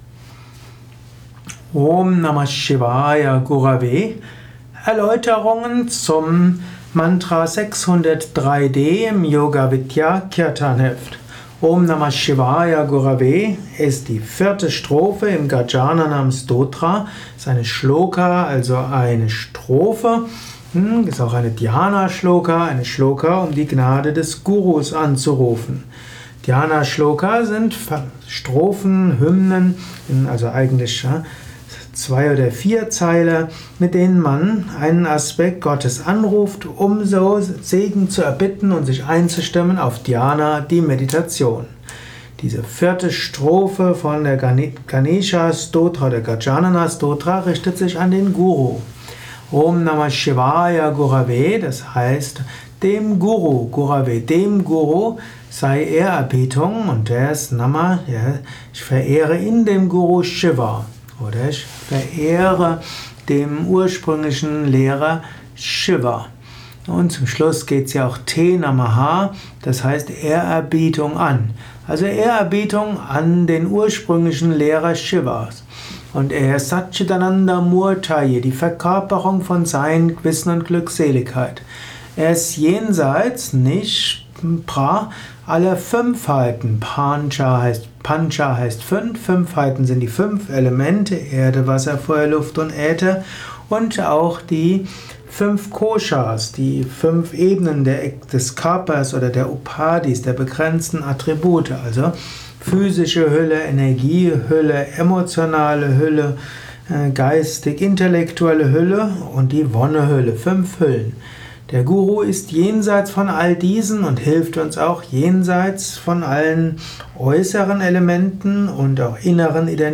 Rezitation
Aufnahme im Yoga Vidya Ashram Bad Meinberg.